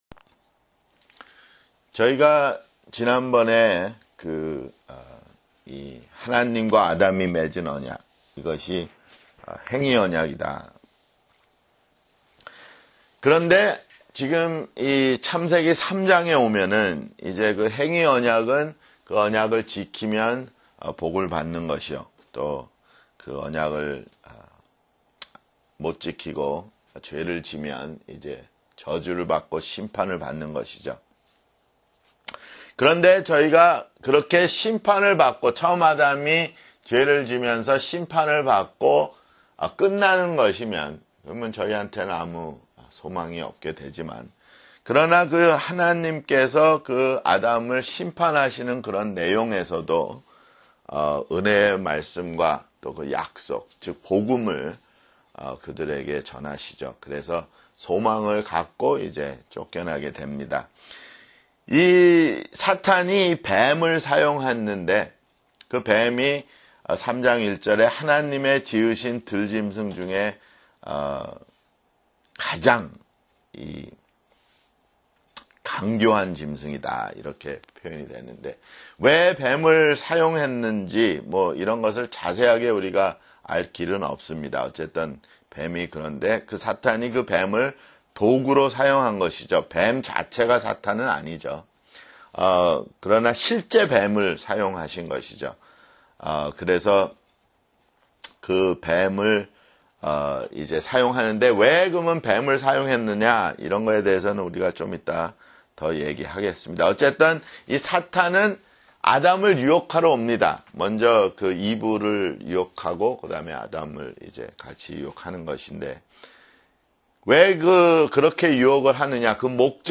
[성경공부] 창세기 (15)